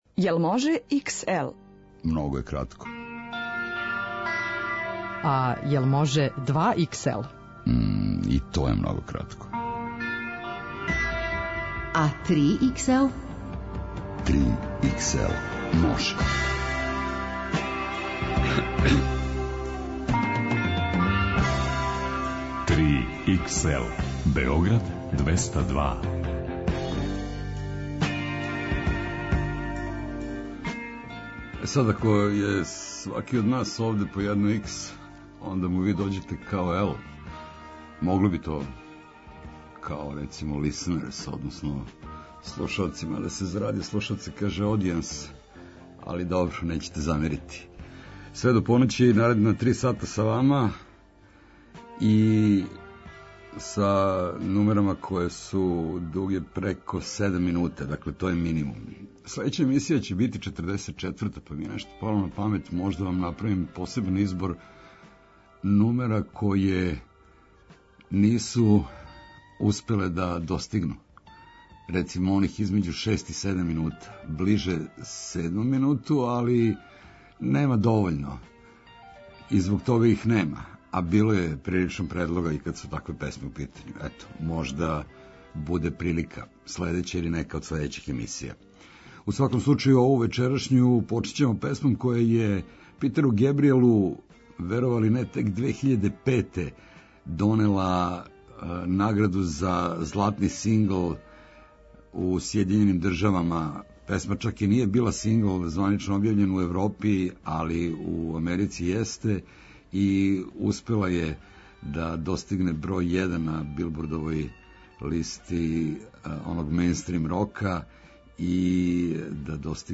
Најдуже музичке нумере.